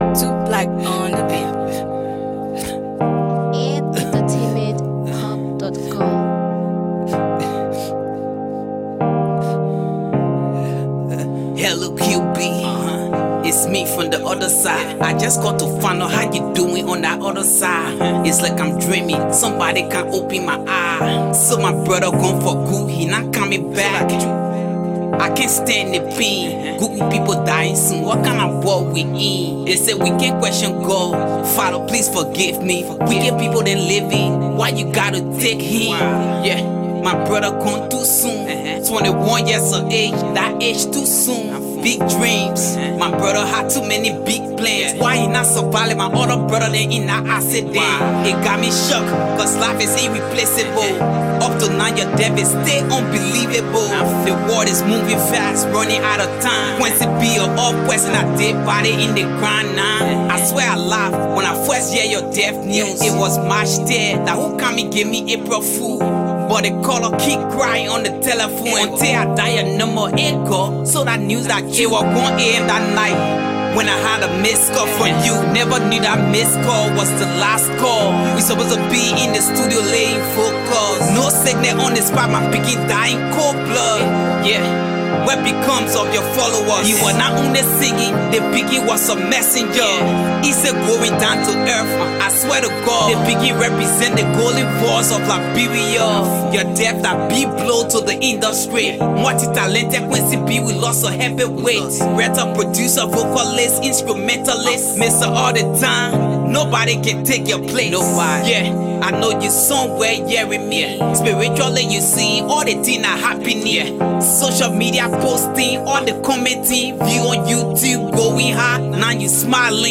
/ Hip-Co, Hip-Hop, RnB / By